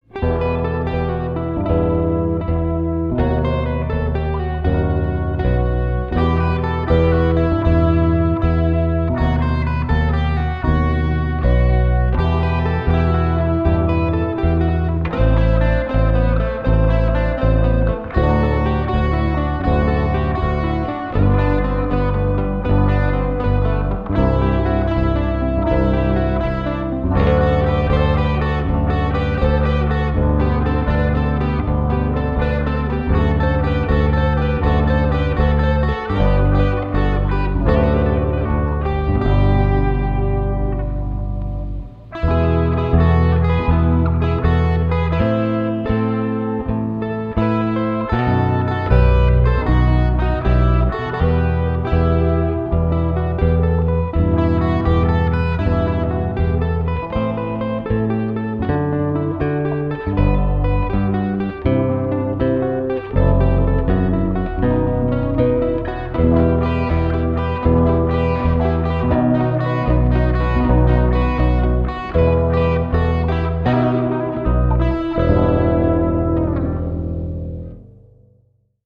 sauf que là, c'est pour deux LesPaul
À gauche : micro manche, à droite : micro chevalet
+ 3/4 caisse pour les accords (bien en haut du manche)
+ basse